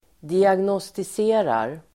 Ladda ner uttalet
Uttal: [diagnåstis'e:rar]